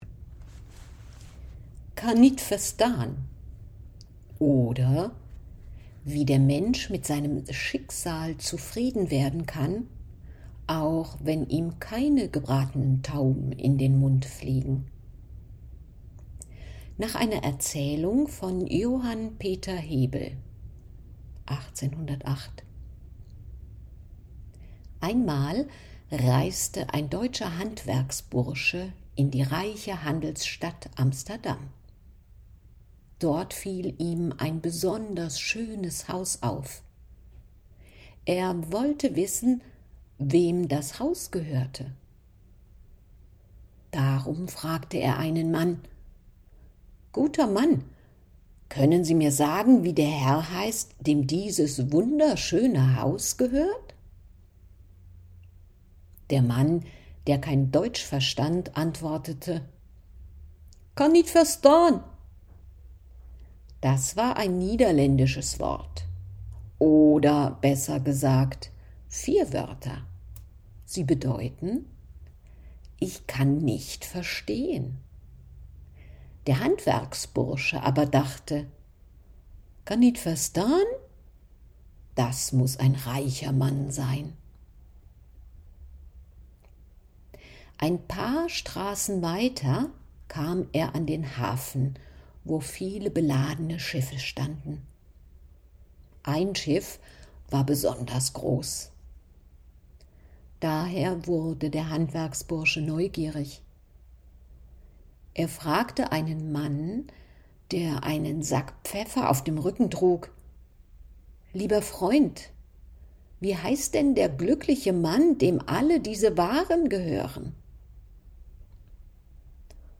Kannitverstan_Lesestuck.mp3